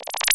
menu_back.wav